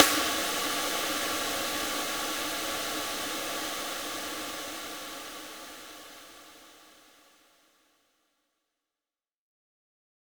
Big Drum Hit 18.wav